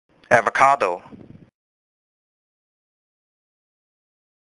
老美都是這麼唸！
這才是道地的美式發音喔！
第一個音節有次重音，而主重音落在“ca”音節 。